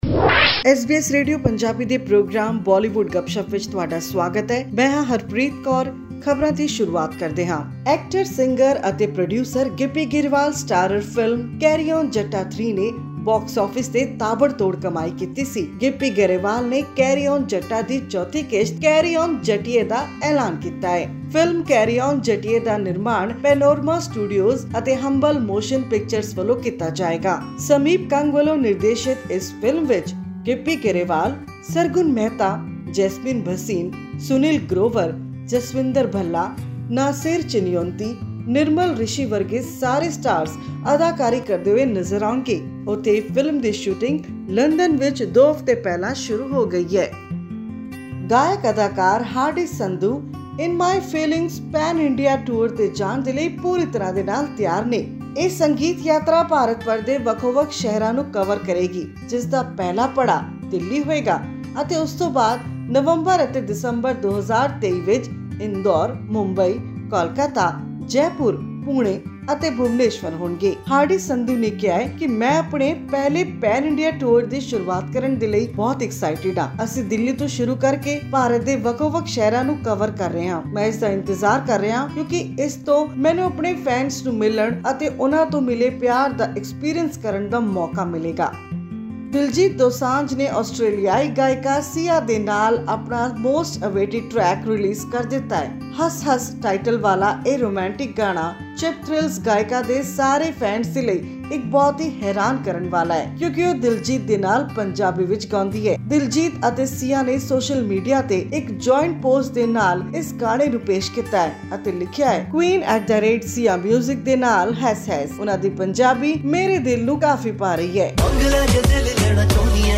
Gippy Grewal and Sargun Mehta are set to bring back the laughter in 2024 with another installment of the Carry on Jatta series, promising a delightful twist for their audience. To know more about this and about upcoming numbers and other movies, listen to our weekly news bulletin from Bollywood.